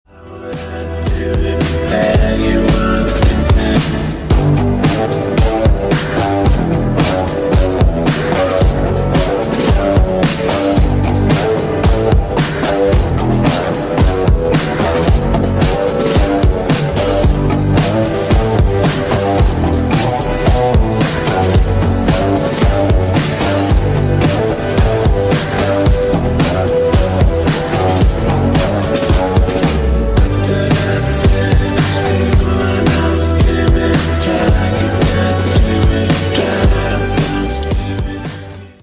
Pop & Rock
Indie Indie Rock Psychedelic Pop